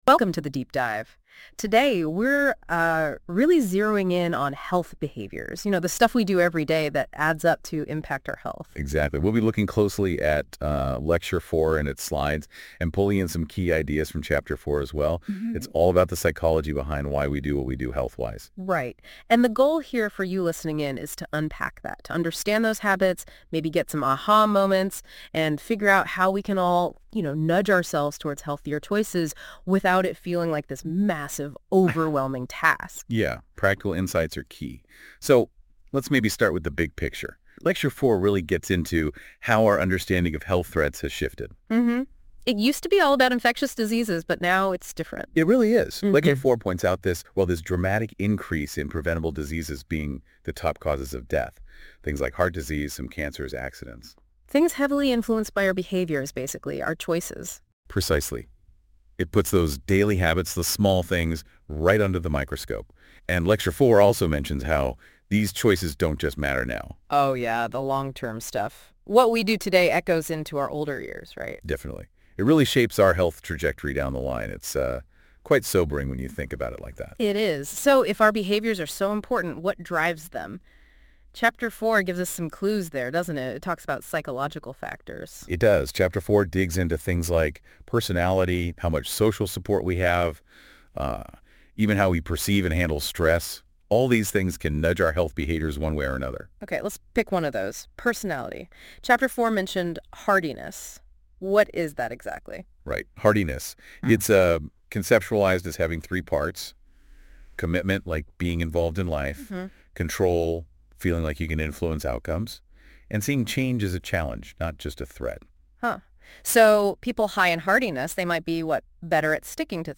hp_lecture4_podcast.mp3